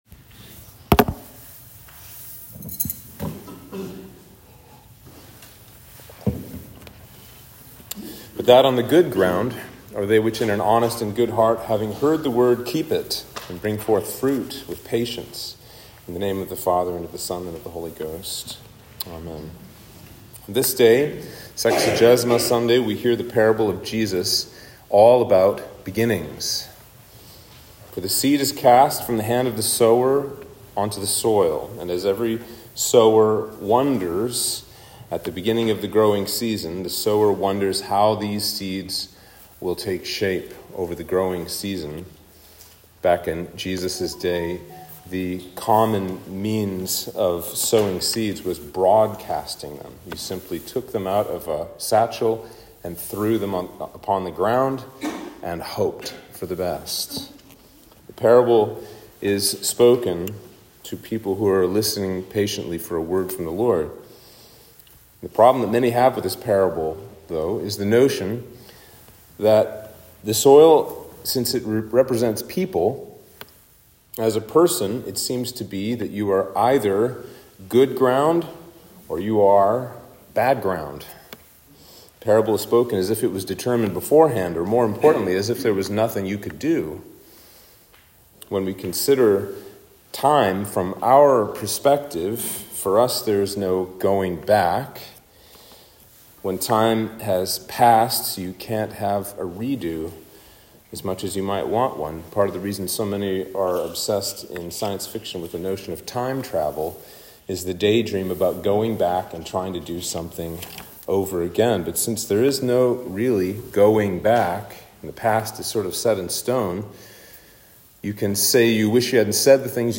Sermon for Sexagesima Sunday